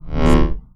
SCIFI_Energy_Pulse_04_mono.wav